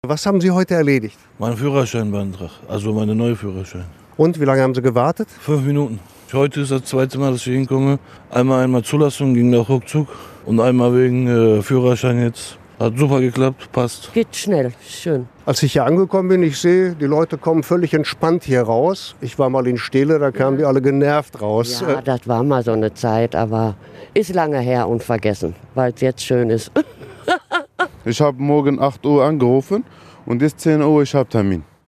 Neue Behörde - Reaktionen der Kundschaft